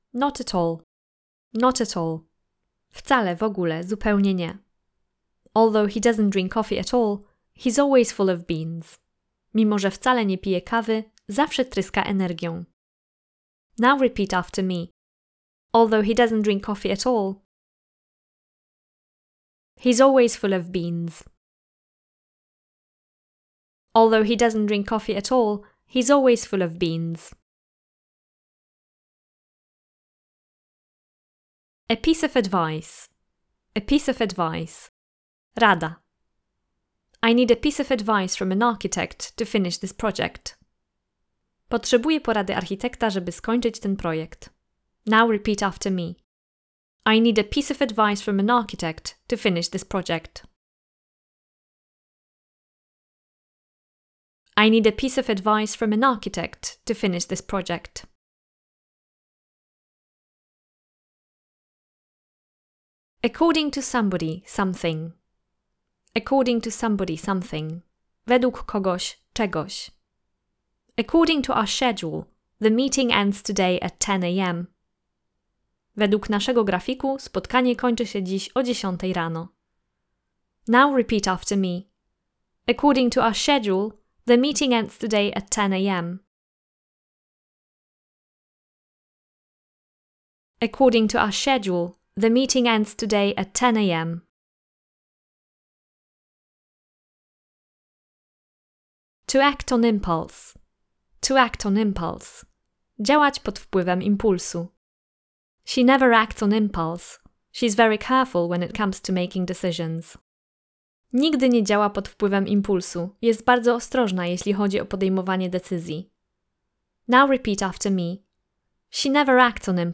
🎧 W KURSIE AUDIO ZNAJDZIESZ: 48 nagrań audio, czyli ponad 190 wyrażeń w kontekście; każde trwa około 2-2,5 min. i zawiera 4 wyrażenia w przykładowych zdaniach po polsku i po angielsku; w każdej audio lekcji daję Ci czas na powtarzanie zdań za mną; transkrypcję do nagrań w formie notatek z kursu (plik pdf, ok. 20 stron); możesz więc słuchać i powtarzać za mną, jednocześnie patrząc na tekst. Sprawdź przykładowe nagranie audio